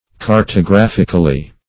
Cartographically \Car`to*graph"ic*al*ly\, adv.